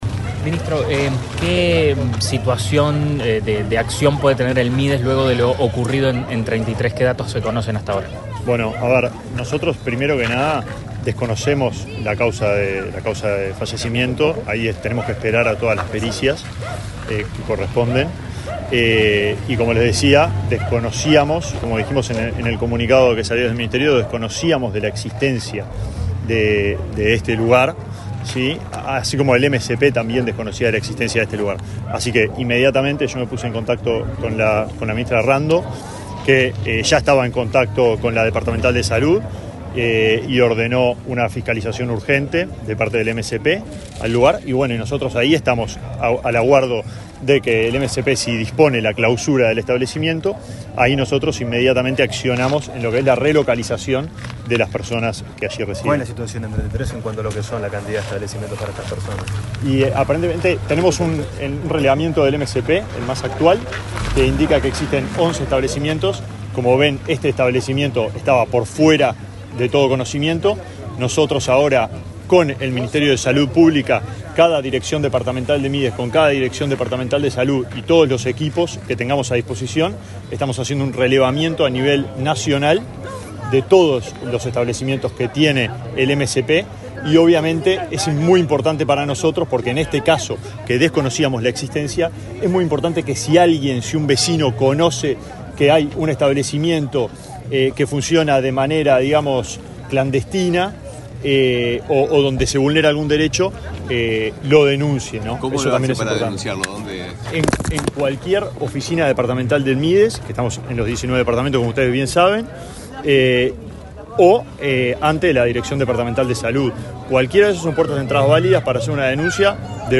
Declaraciones del ministro de Desarrollo Social, Alejandro Sciarra
Luego, dialogó con la prensa.